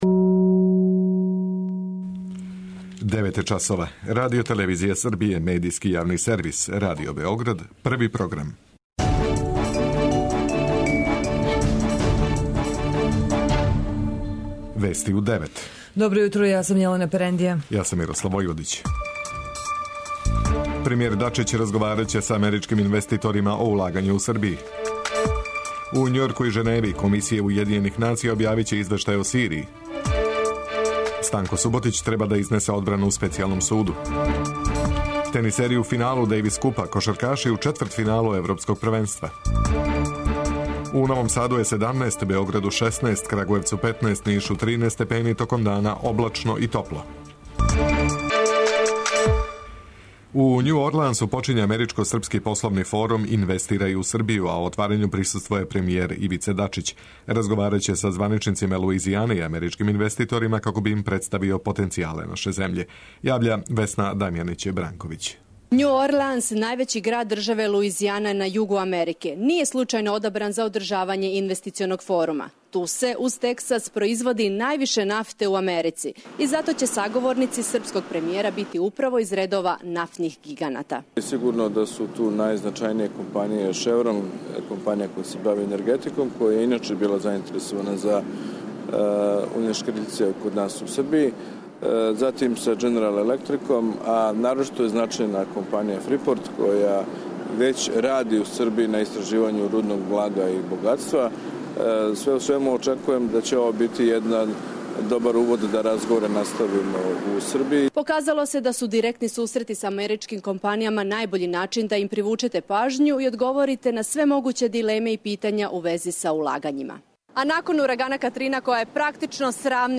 Вести у 9